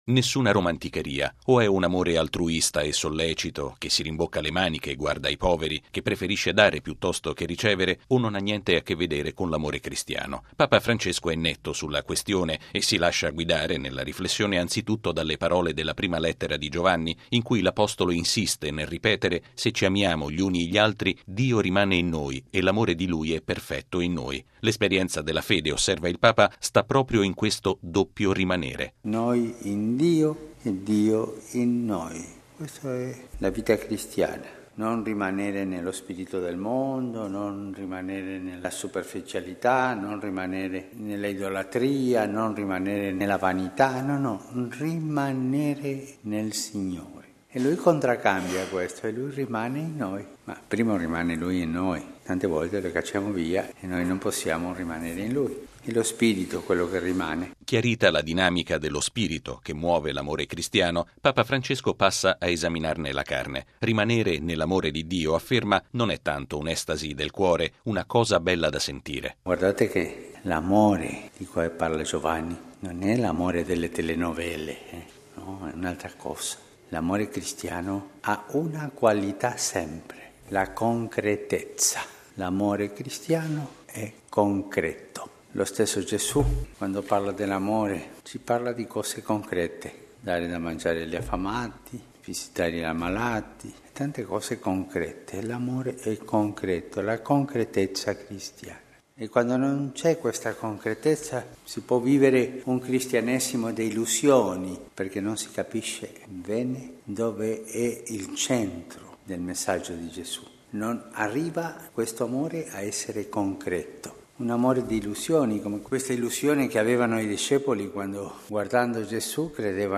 Quindi, è un amore che “è più nelle opere che nelle parole”, è “più nel dare che nel ricevere”. Lo ha riaffermato questa mattina Papa Francesco, all’omelia della Messa presieduta in Casa Santa Marta.